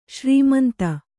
♪ śrī manta